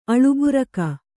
♪ aḷuburaka